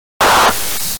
file) 18 KB halt damage sound 1